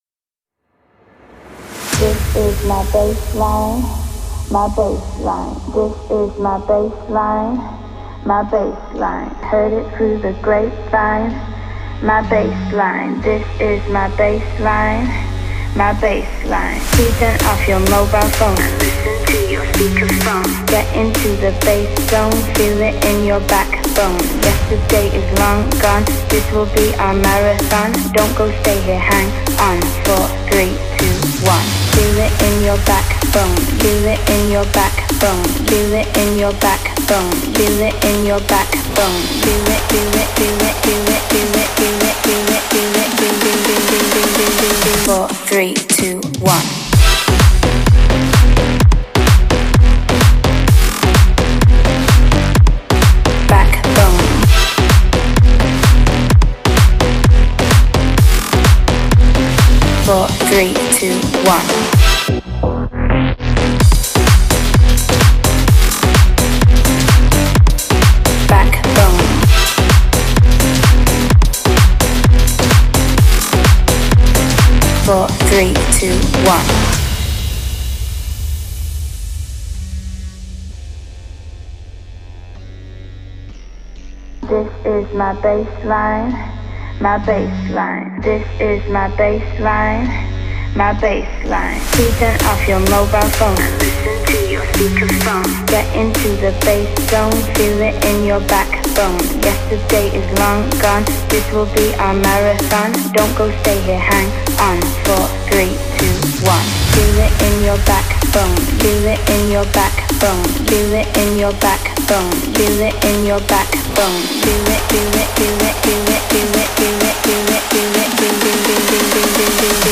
это зажигательный трек в жанре EDM